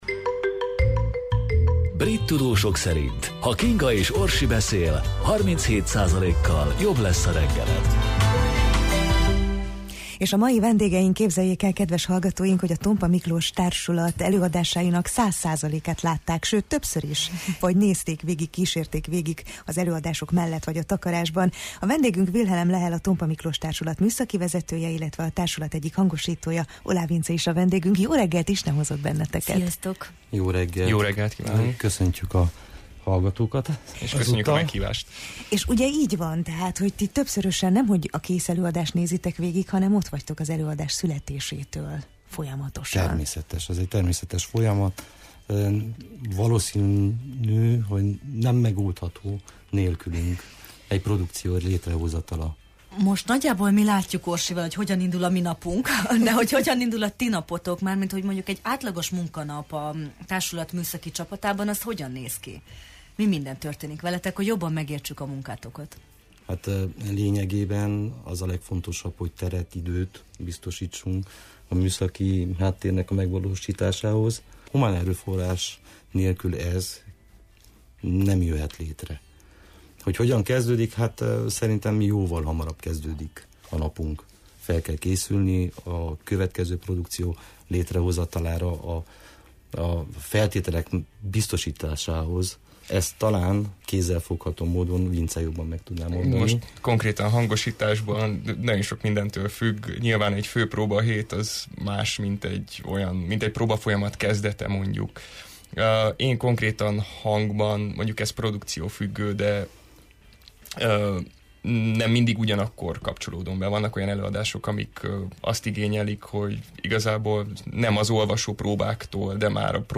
Velük beszélgettünk a színházi háttérmunkáról, kihívásokról és a szakma szépségeiről: